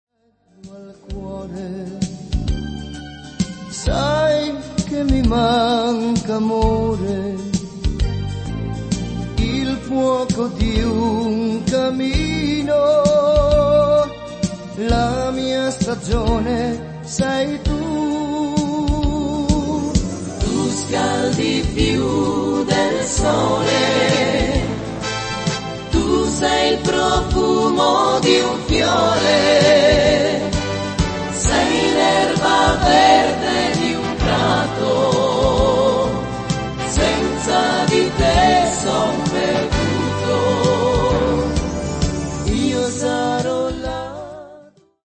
valzer lento